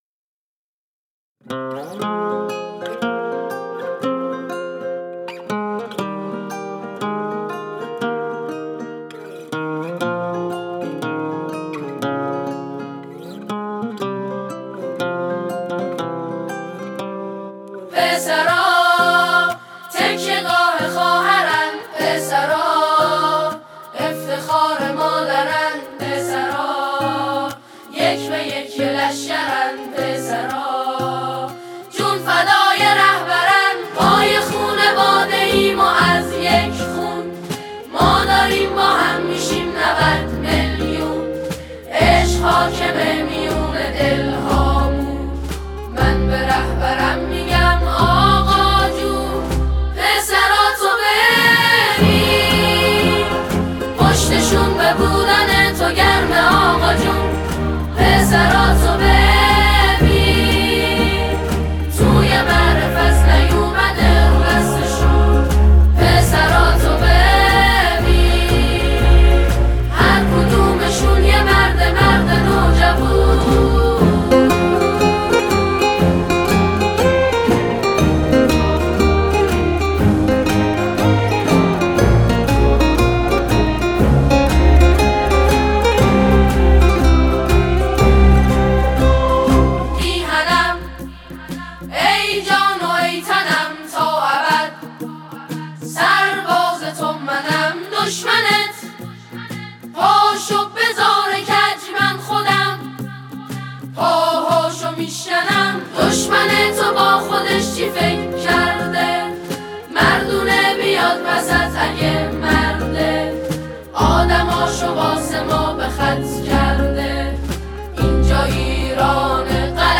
برای شنیدن قطعه با کلام